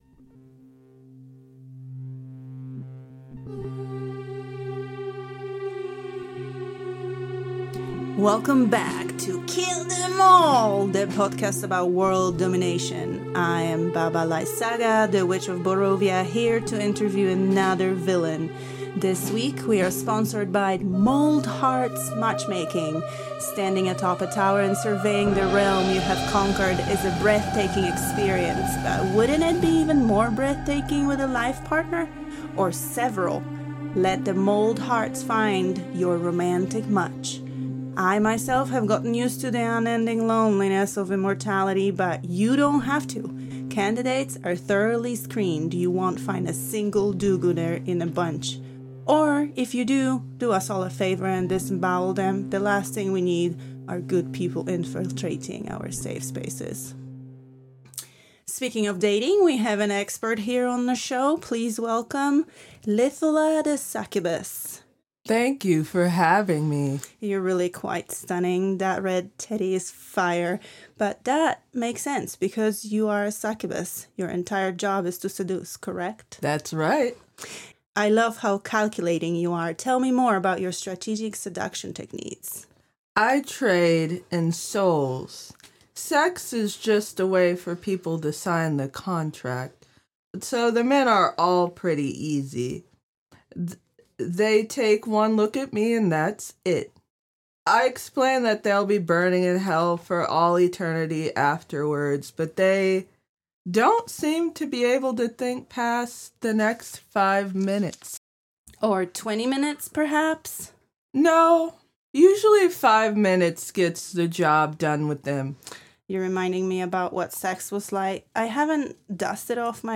Format: Audio Drama
Voices: Solo
Genres: Comedy